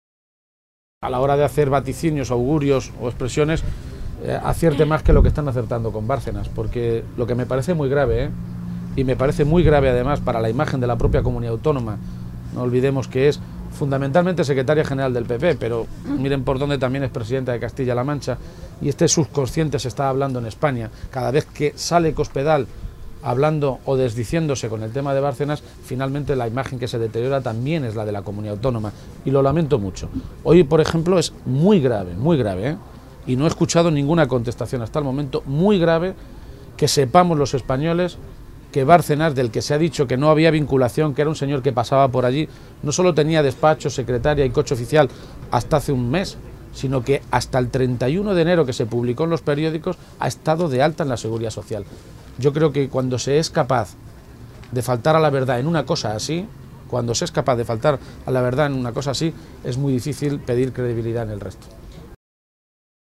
En otro orden de cosas, y preguntado también por los periodistas, Page contestaba sobre las informaciones que han revelado que el secretario general del PSOE castellano-manchego fue objeto de seguimientos y espionajes por parte de la agencia de detectives Método 3.
Cortes de audio de la rueda de prensa